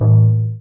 Stone Axe 1.ogg